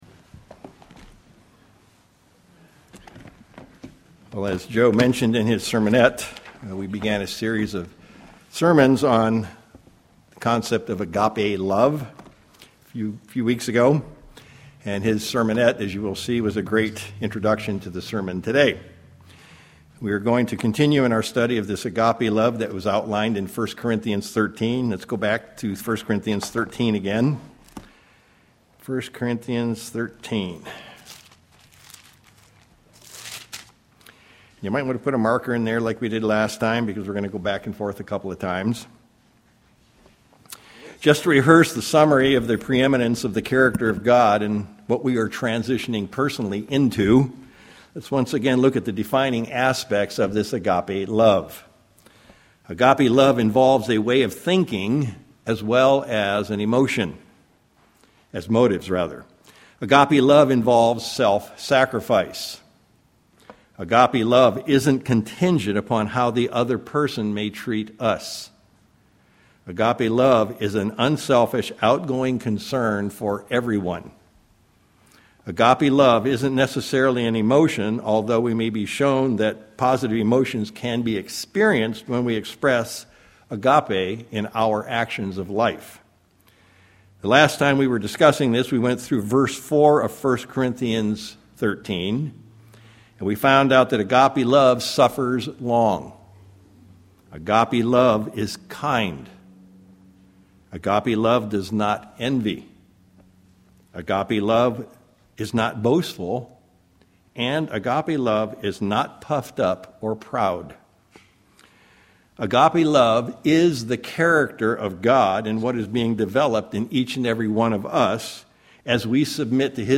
Sermons
Given in Sacramento, CA